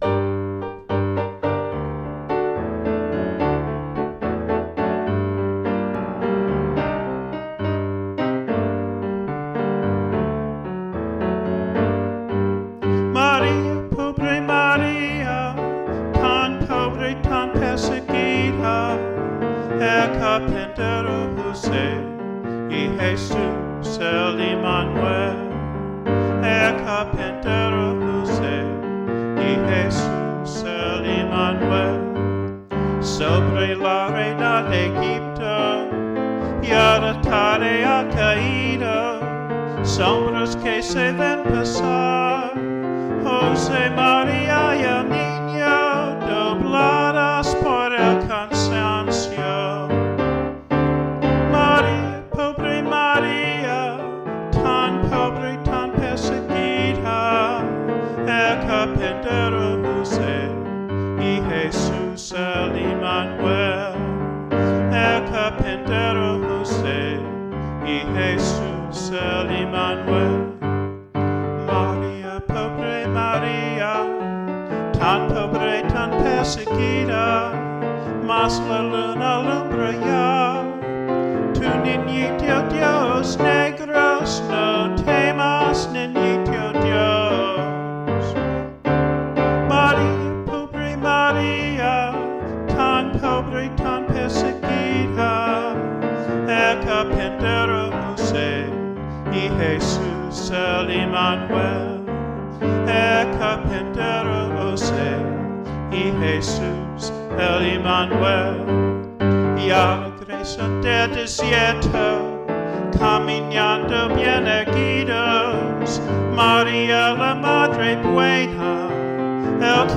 favorite Spanish Christmas carol: